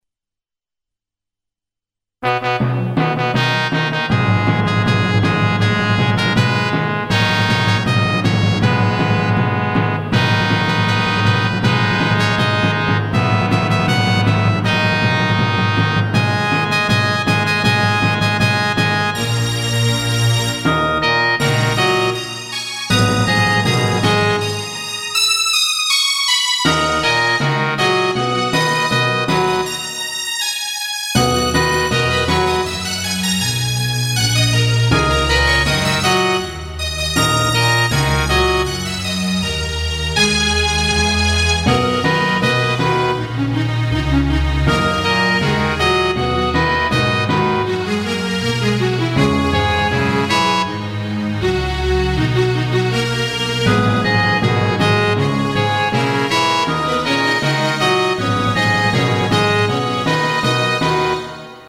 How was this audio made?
Computer Version- Digital Sound by Yamaha, Sibelius, HP and SoundBlaster